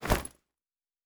Bag 10.wav